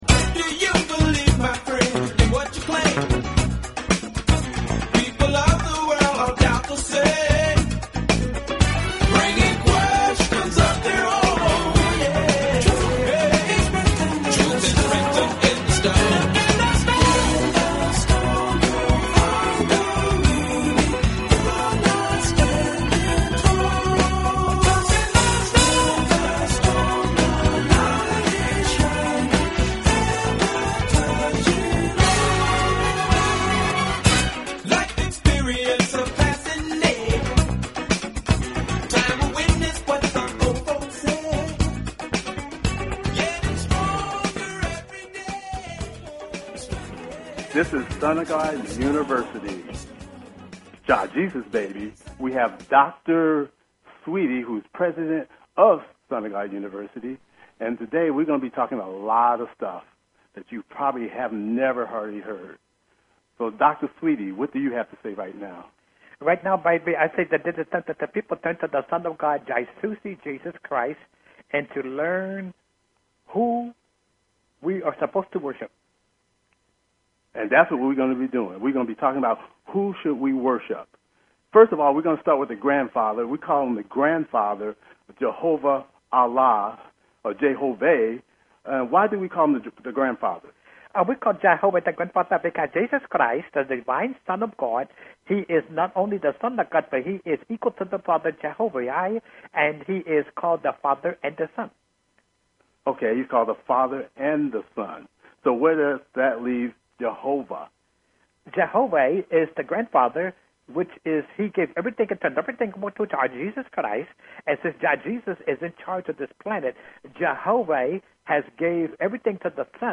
with The Hosts